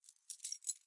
钥匙扣 " 钥匙扣16
描述：录音设备：Sony PCMM10Format：24 bit / 44.1 KHz
Tag: 样品 记录 弗利